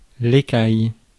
Prononciation
Synonymes arctidé Prononciation France: IPA: /e.kaj/ Accent inconnu: IPA: /ekɑj/ Le mot recherché trouvé avec ces langues de source: français Traduction 1.